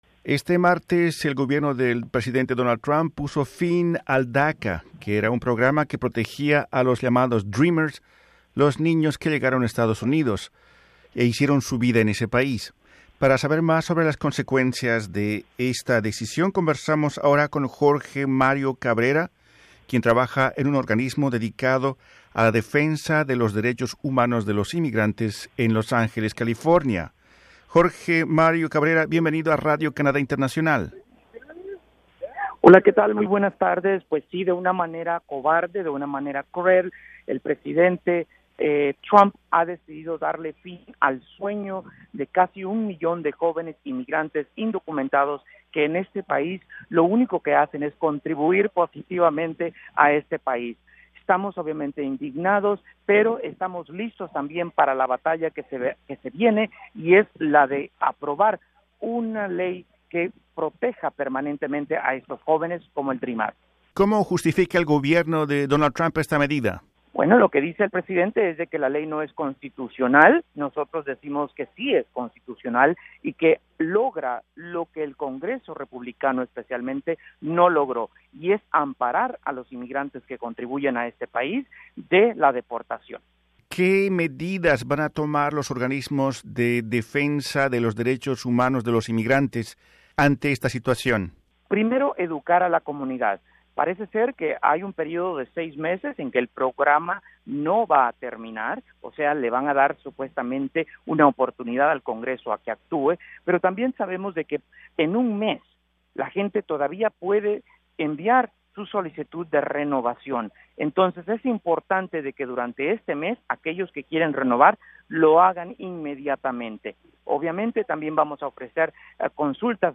Para saber más sobre la situación, Radio Canadá Internacional pudo conversar en Los Angeles, California